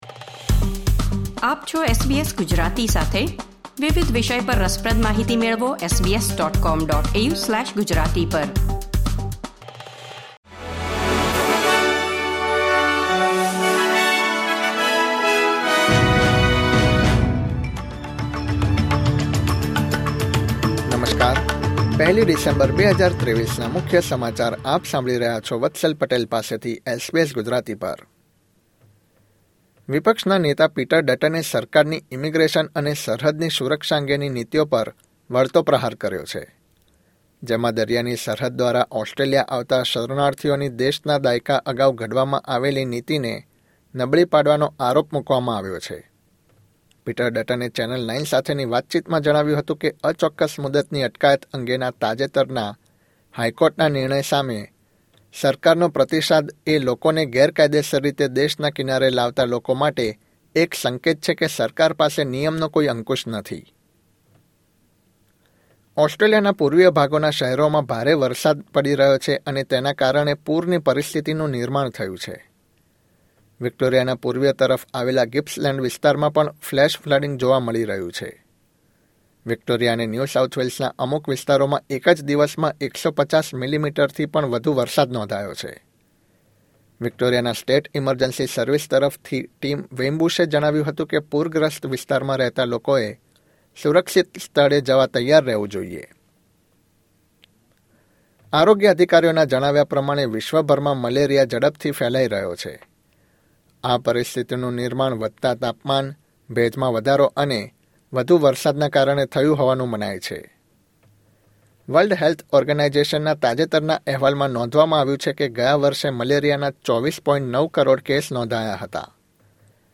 SBS Gujarati News Bulletin 1 December 2023